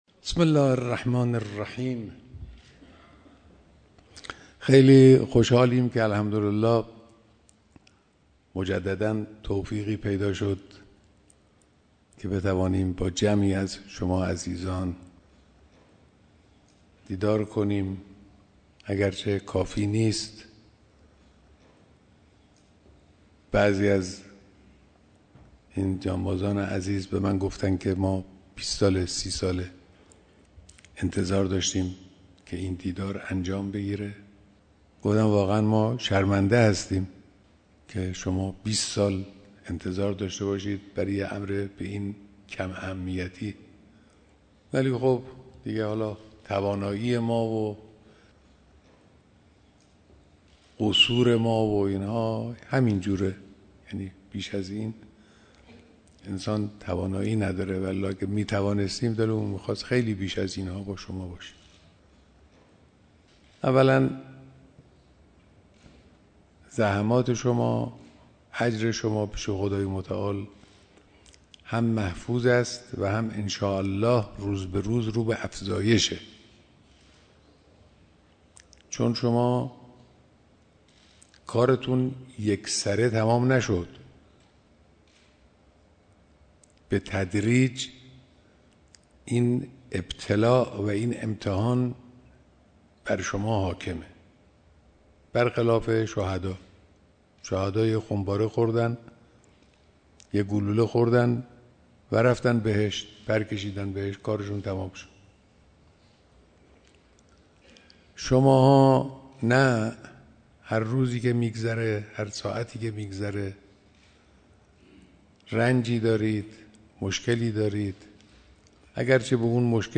بیانات در دیدار جمعی از جانبازان قطع نخاعی و بالای ۷۰ درصد